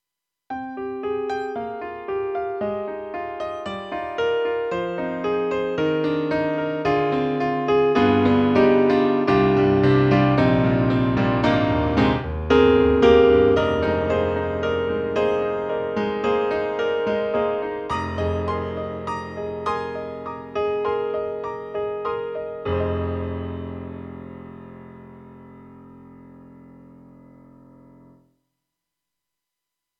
Program_Stereo Concert Piano
KORG_M3 XPanded_Program_Stereo Concert Piano.mp3